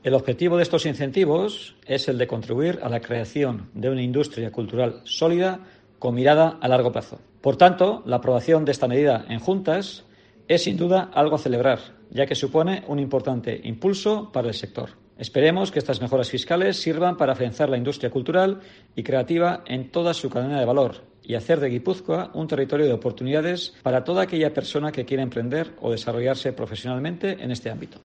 Jokin Perona, diputado de Hacienda y Finanzas de Gipuzkoa